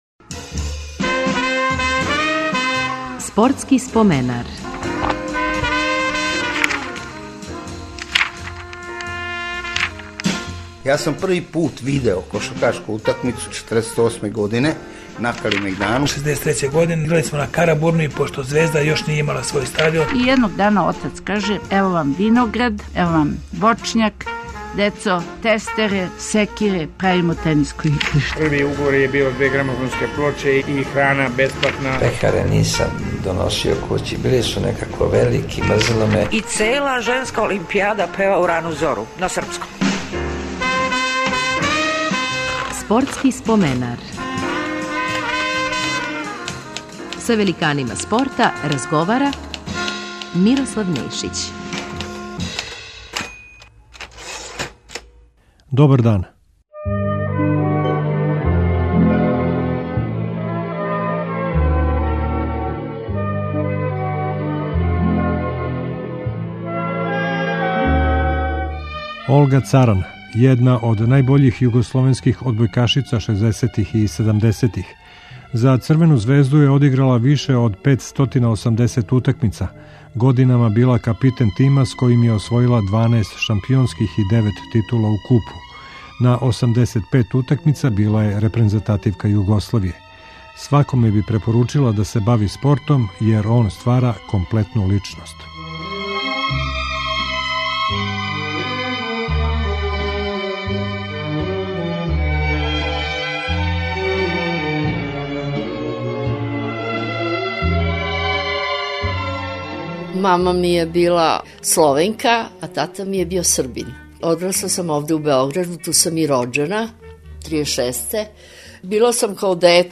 Говорећи о својим почецима, успонима и падовима, поразима и победама, највећим успесима, истакнути спортисти који су се тамичили у протеклих седам деценија стварају слику спорта на овим просторима.